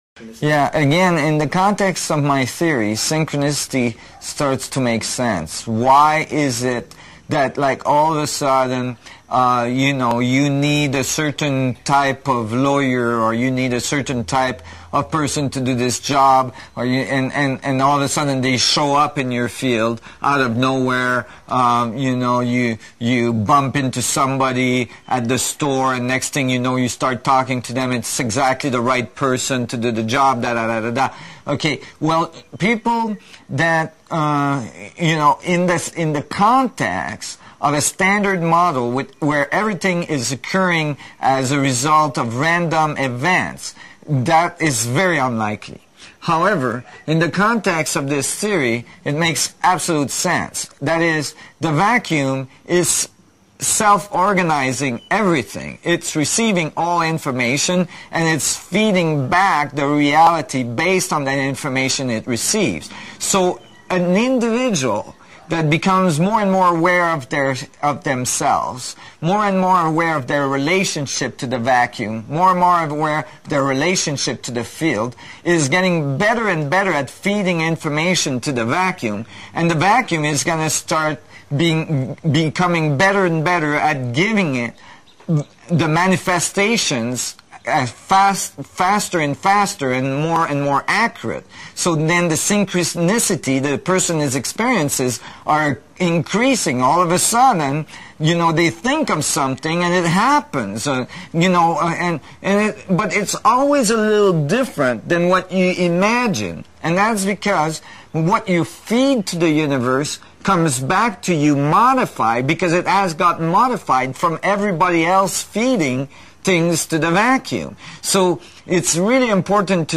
The last part of the video (on the right) includes an interview with Nassim Haramein where he explains how everything fits together – nothing is random or a coincidence.